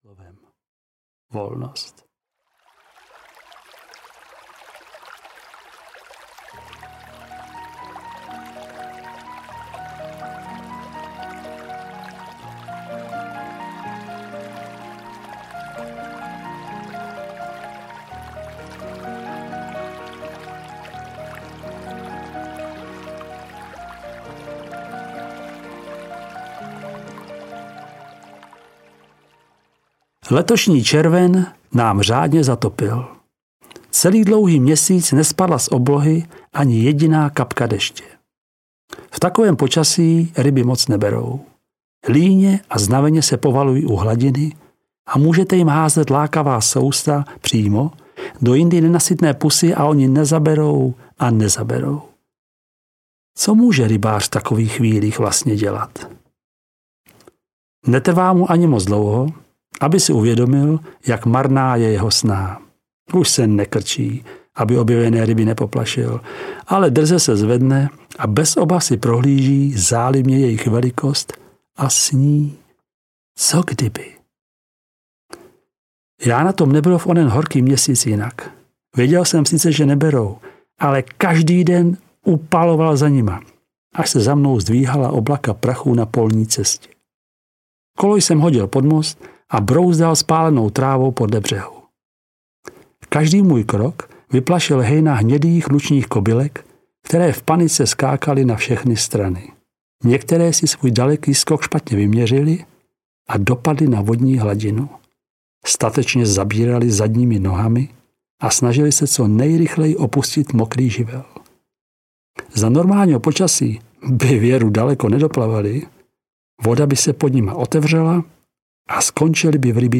Stříbrný tloušť audiokniha
Ukázka z knihy